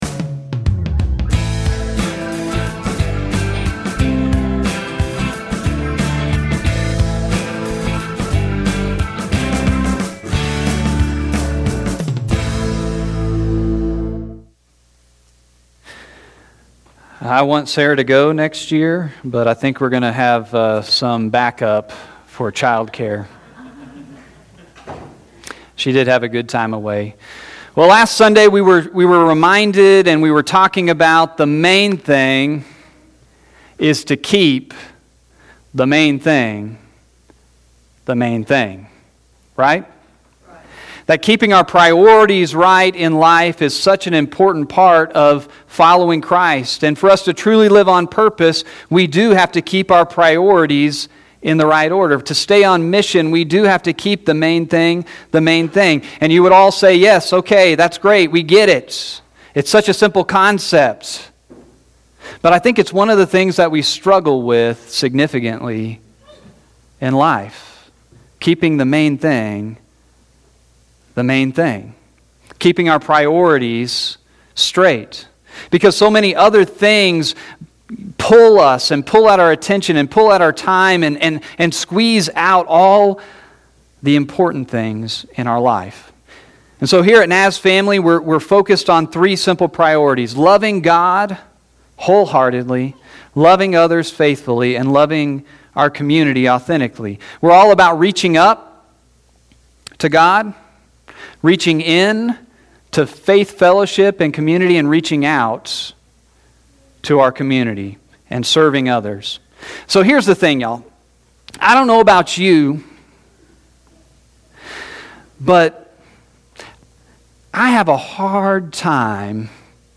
Play Rate Listened List Bookmark Get this podcast via API From The Podcast Sermons from the NazFamily Church in Big Spring, Texas.